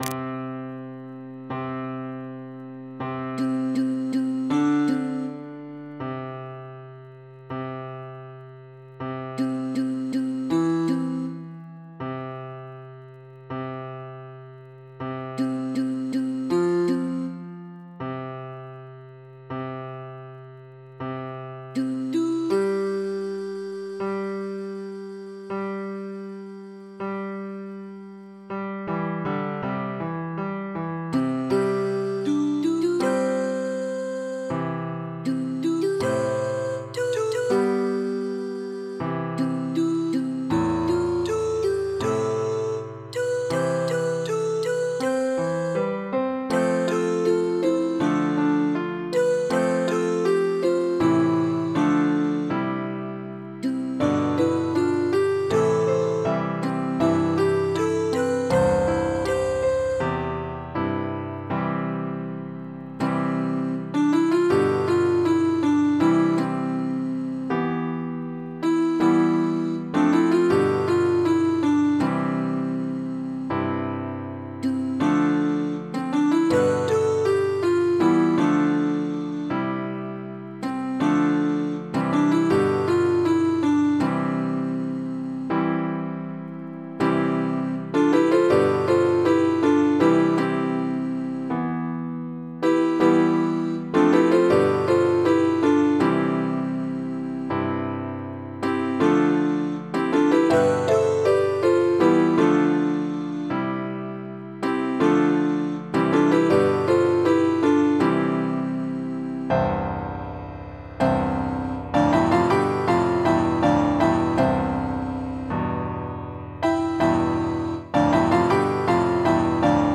Invisible_Republic-from-midi.mp3